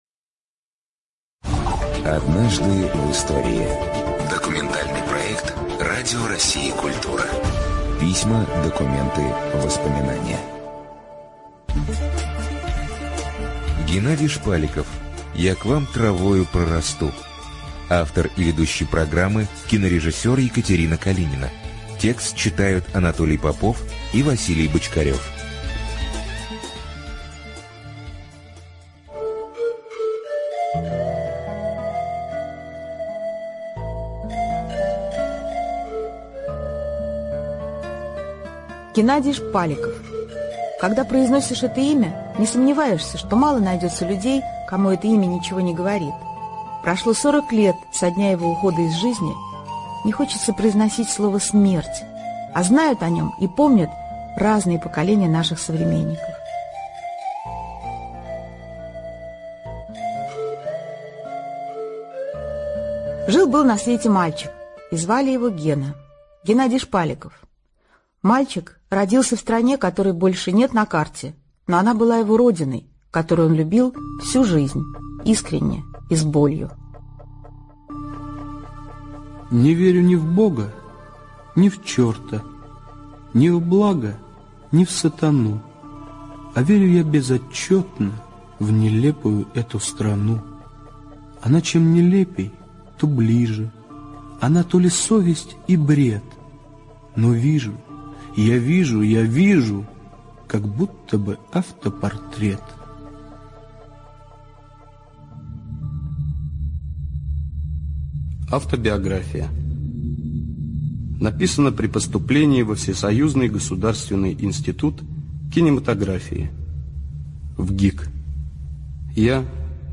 аудиоспектакль